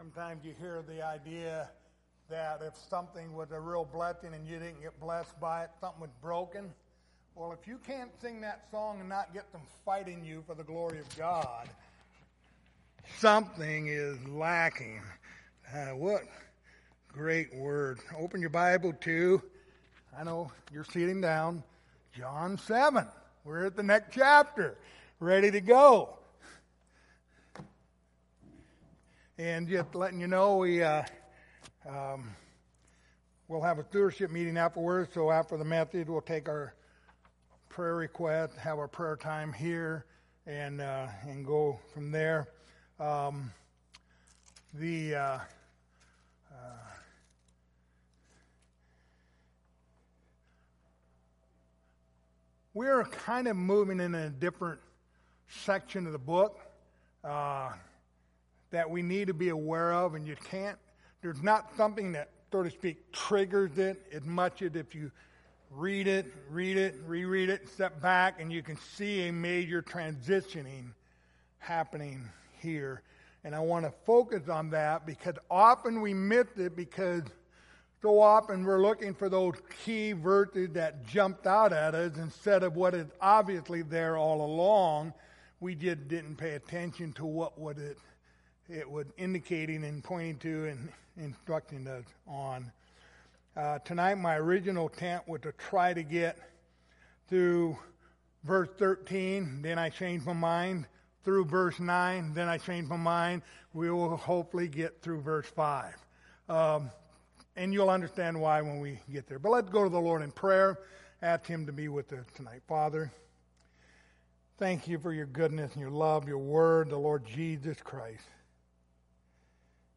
Passage: John 7:1-4 Service Type: Wednesday Evening